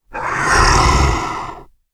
Monster Breathe Sound
horror
Monster Breathe